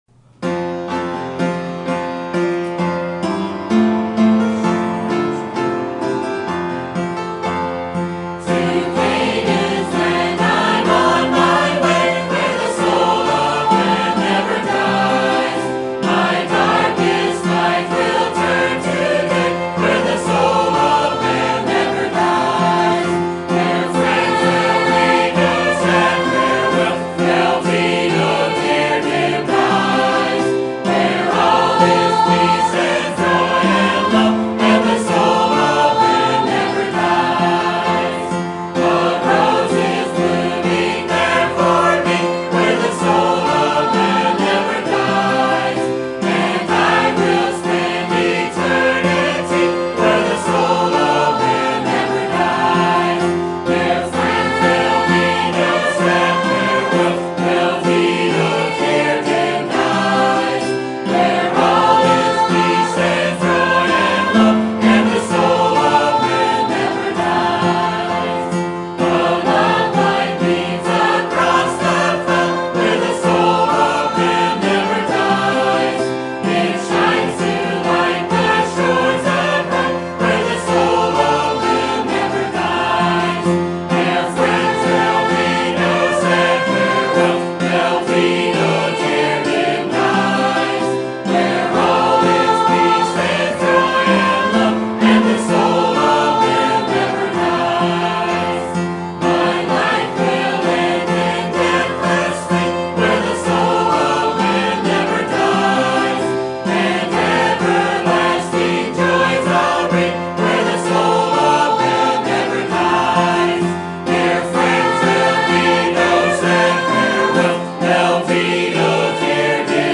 Sermon Topic: Baptist History Conference Sermon Type: Special Sermon Audio: Sermon download: Download (32.52 MB) Sermon Tags: Acts Baptist History Liberty